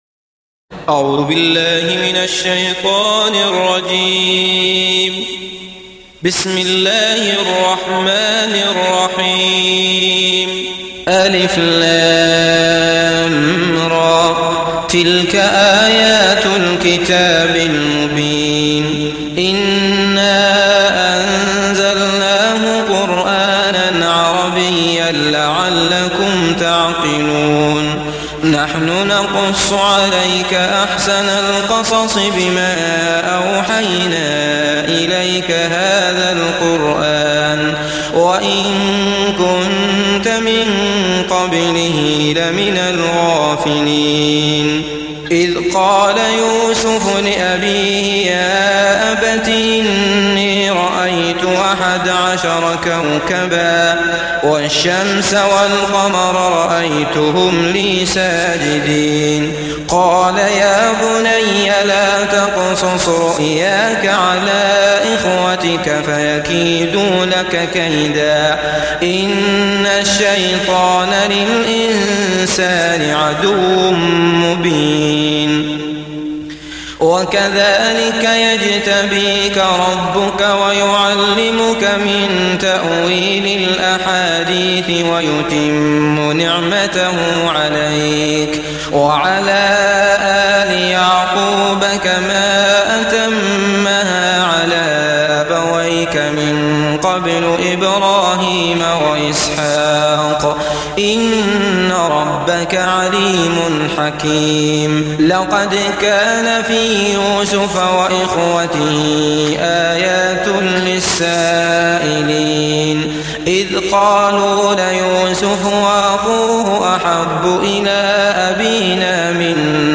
Surat Yusuf Download mp3 Abdullah Al Matrood Riwayat Hafs dari Asim, Download Quran dan mendengarkan mp3 tautan langsung penuh